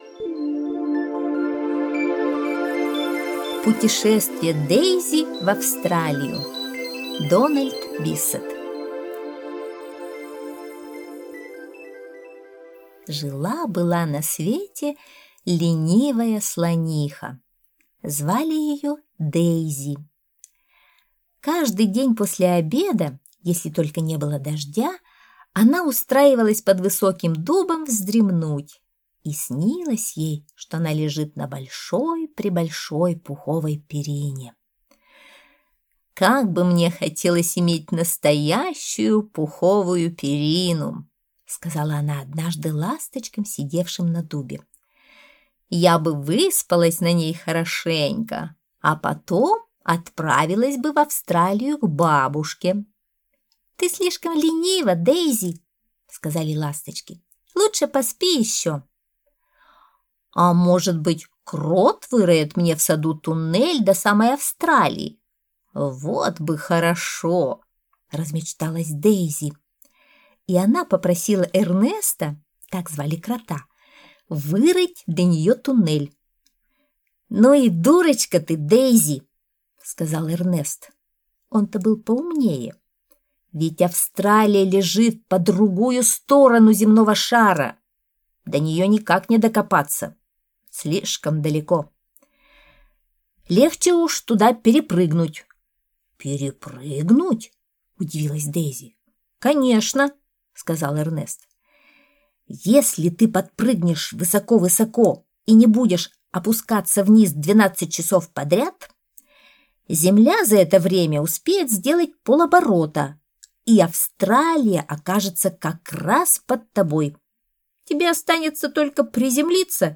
Аудиосказка «Путешествие Дейзи в Австралию»